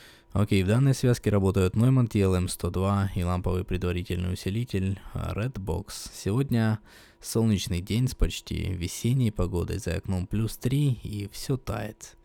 Вложения tlm102 RedBox.wav tlm102 RedBox.wav 3,4 MB · Просмотры: 180 433d52_9c7e85b69fdf45a480170f86d24af25d.jpg_srb_p_611_458_75_22_0.50_1.20_0.00_jpg_srb.jpg 77 KB · Просмотры: 192